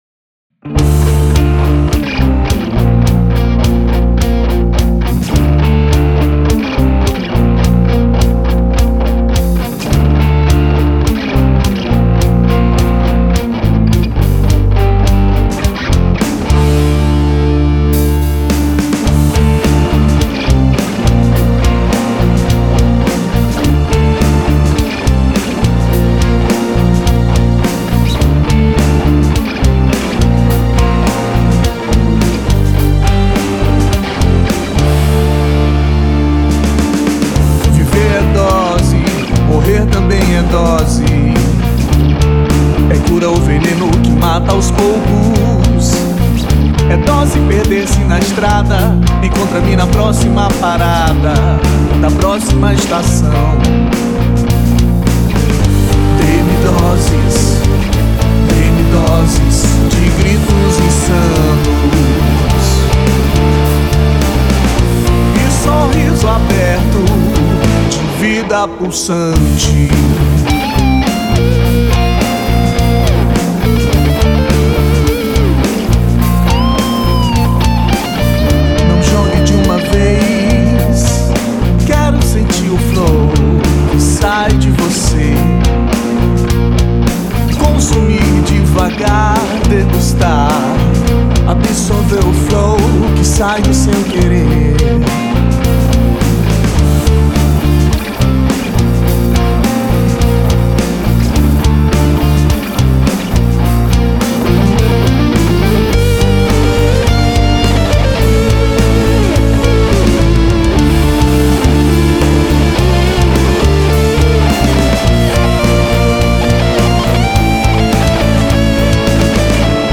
486   05:16:00   Faixa:     Rock Nacional